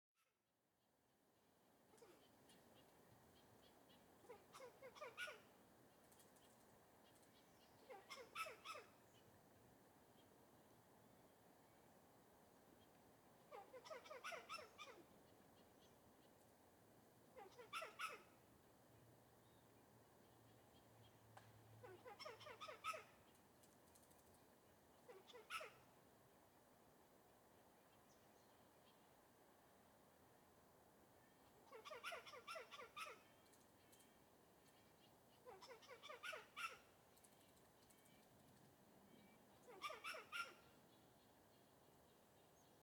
Squirrel Song In California Forest Efecto de Sonido Descargar
Animal Sounds Soundboard108 views